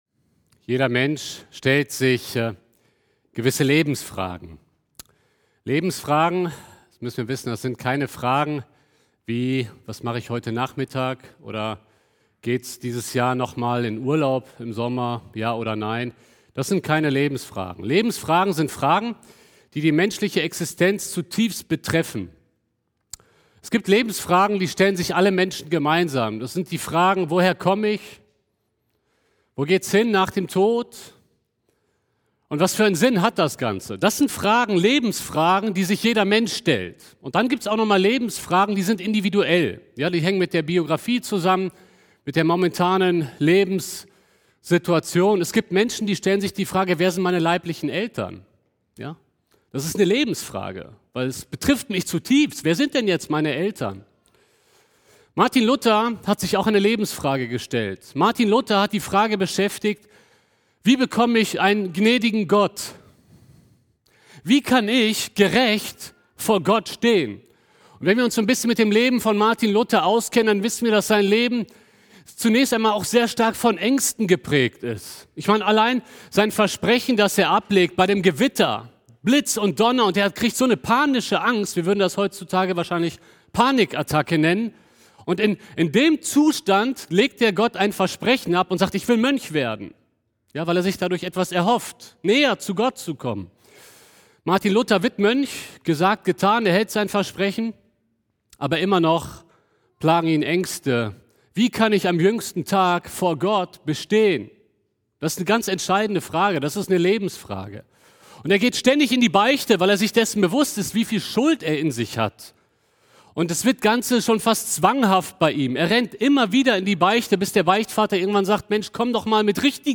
Februar 2021 Predigt-Reihe